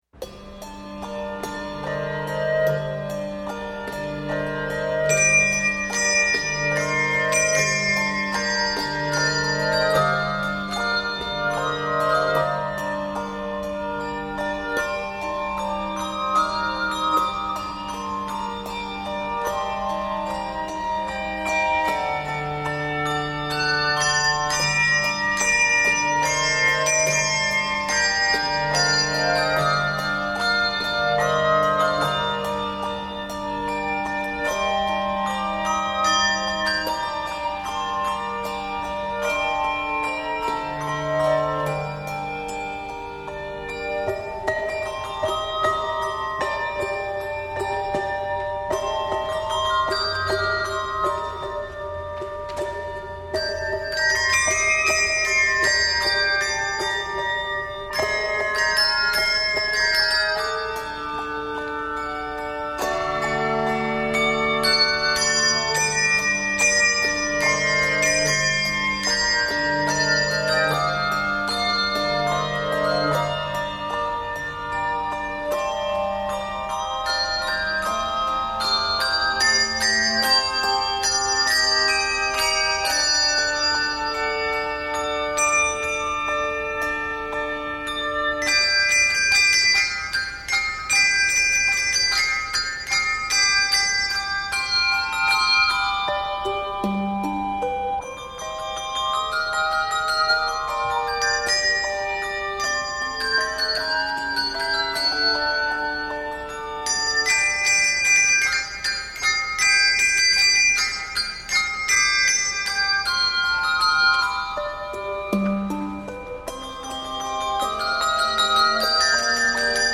Octaves: 4-5 Level